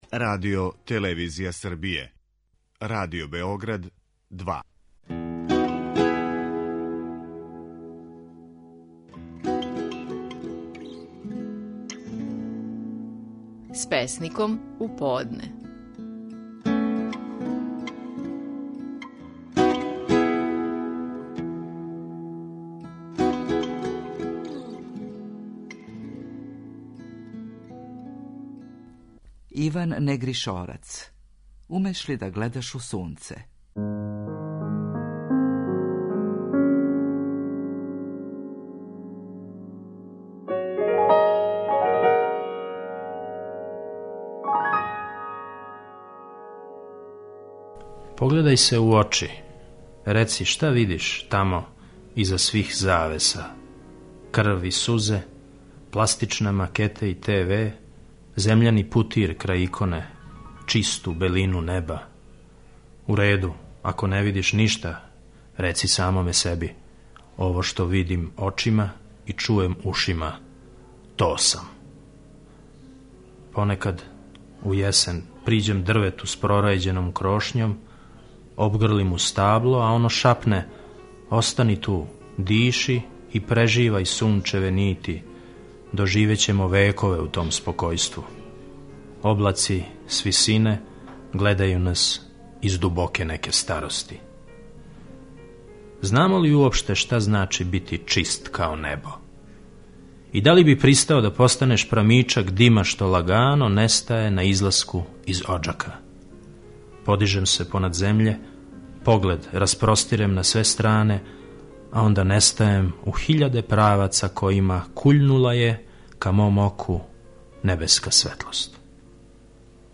Стихови наших најпознатијих песника, у интерпретацији аутора.
Иван Негришорац говори своју песму „Умеш ли да гледаш у сунце".